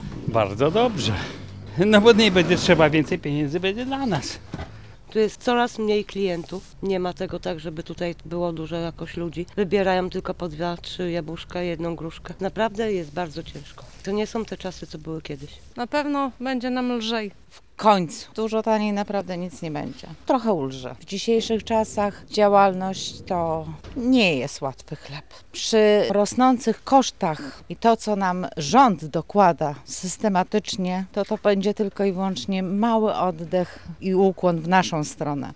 Z handlującymi na targowiskach rozmawiała nasza reporterka.